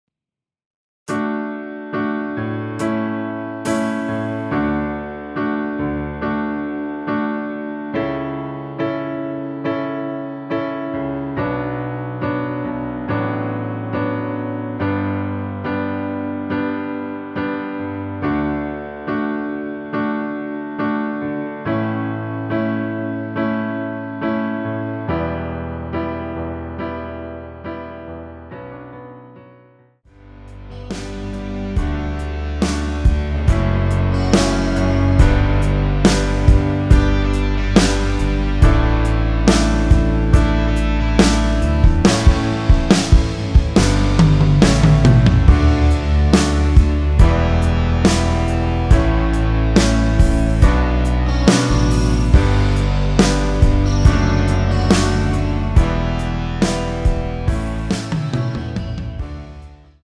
노래하기 편하게 전주 1마디 만들어 놓았습니다.(미리듣기 확인)
원키(1절+후렴)으로 진행되는 MR입니다.
D
앞부분30초, 뒷부분30초씩 편집해서 올려 드리고 있습니다.